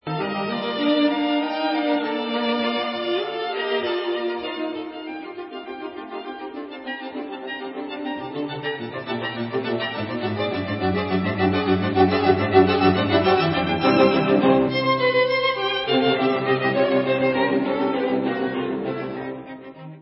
Recording: CHAMBER MUSIC
Poco allegro